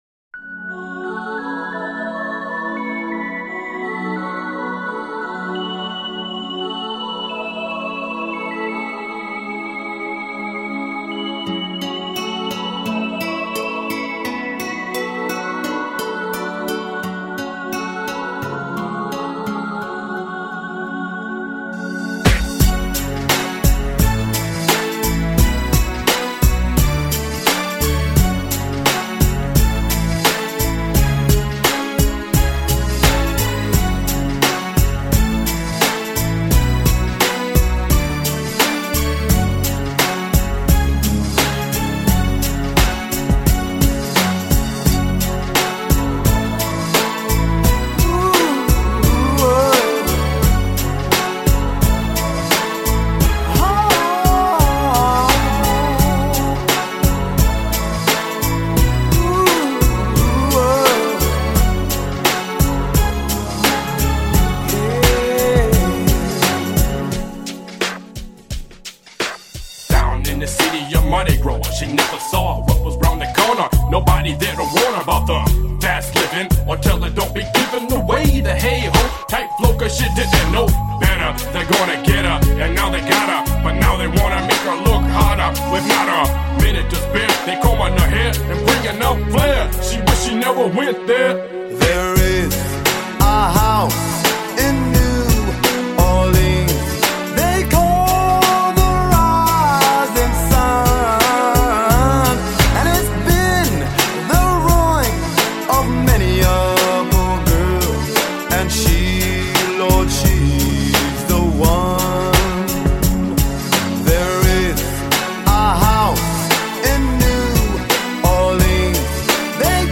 Жанр: R&B